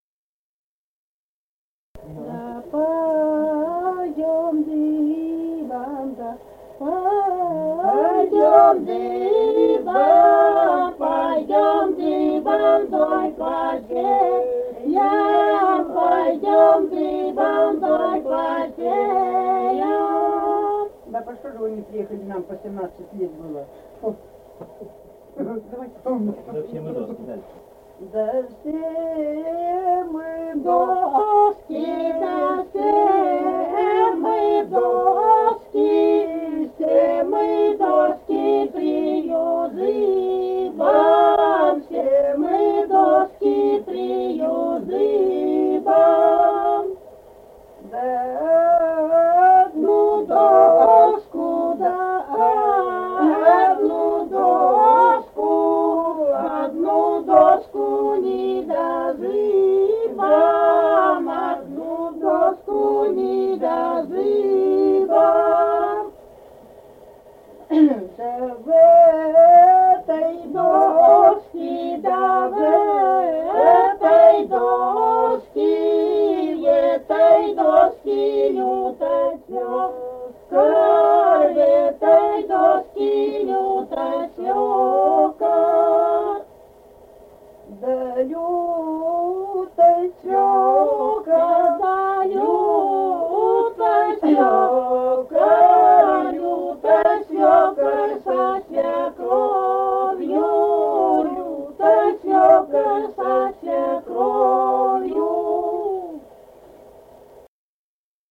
| filedescription = «Да пойдём дыбом», хороводная / «лужошная». Исполняет трио
третья исполнительница не объявлена.
Республика Казахстан, Восточно-Казахстанская обл., Катон-Карагайский р-н, с. Язовая, июль 1978.